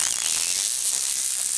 scanner
cbot_battletalk1.ogg